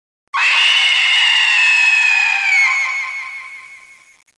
Sound Effects
Scream14